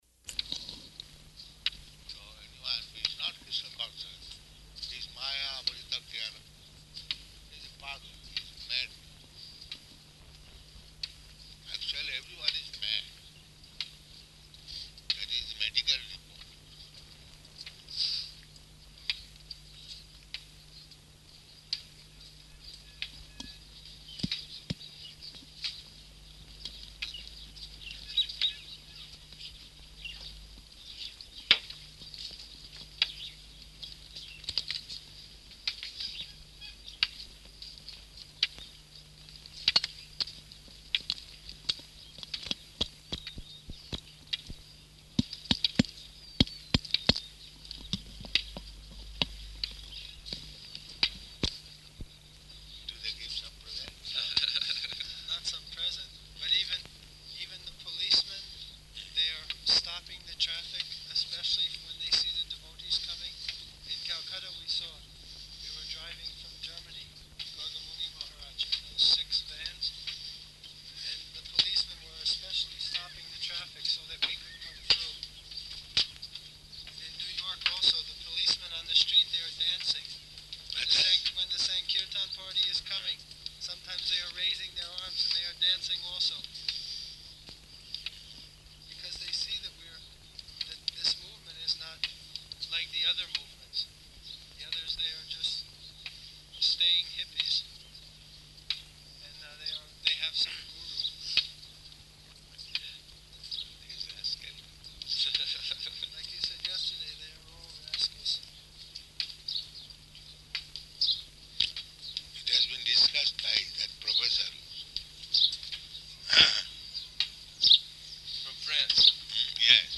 Morning Walk [partially recorded]
Type: Walk
Location: Māyāpur